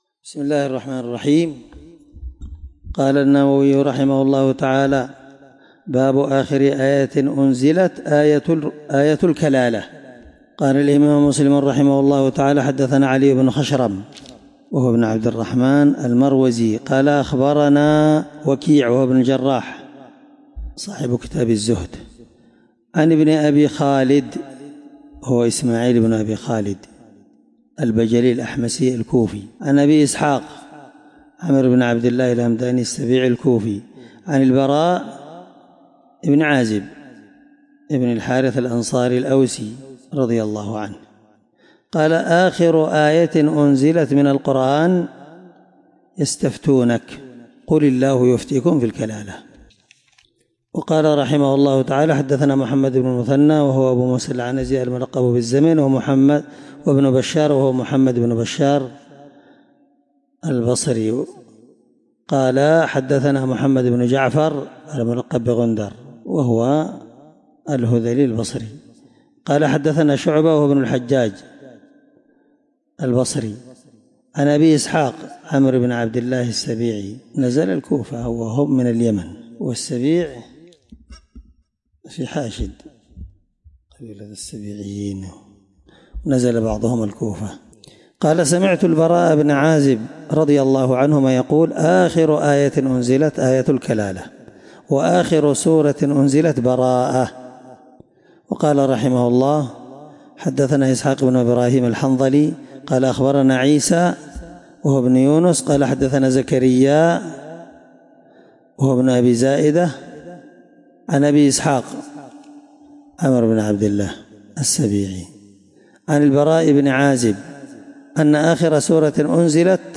الدرس5من شرح كتاب الفرائض حديث رقم(1618) من صحيح مسلم